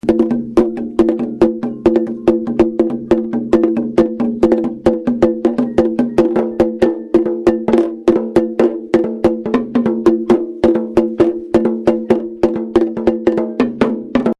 Bata : traditionnellement en bois, cet instrument serait d'origine afro-cubaine.
Tambour Bata